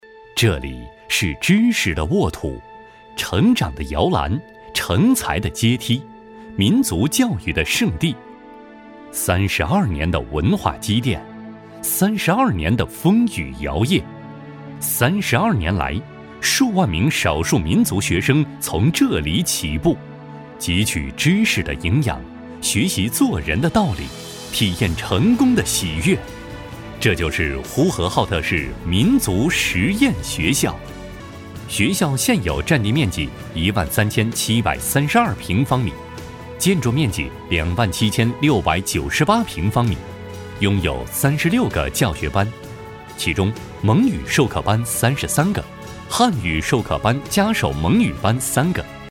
学校男130号（讲述）
激情力度 学校专题
磁性男中音，偏年轻些。擅自专题、新闻、旁白、产品介绍等题材。